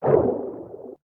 Fish_Attack3.ogg